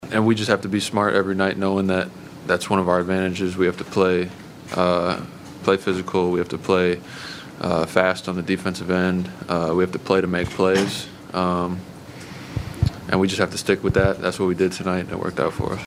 Thunder forward Chet Holmgren says defense will continue to be OKC’s calling card.